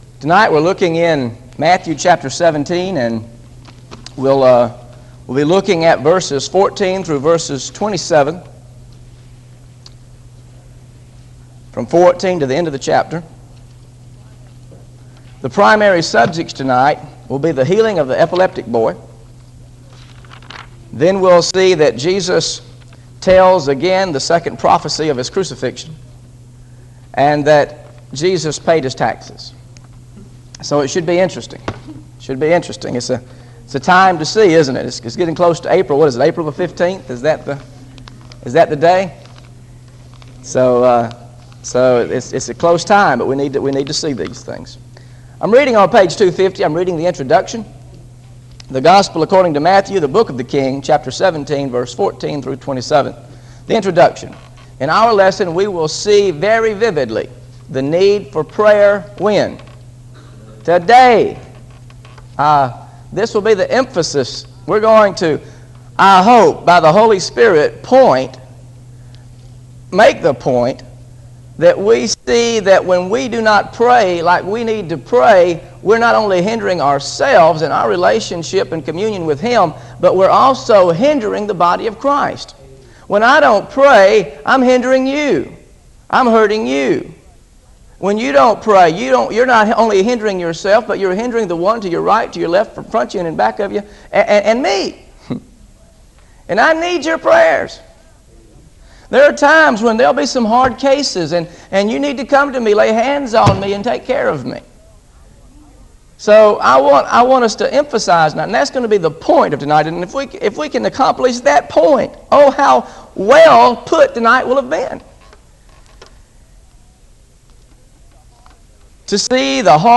GOSPEL OF MATTHEW BIBLE STUDY SERIES This study of Matthew: Matthew 17 Verses 14-27 How to Pray for Hard Cases is part of a verse-by-verse teaching series through the Gospel of Matthew.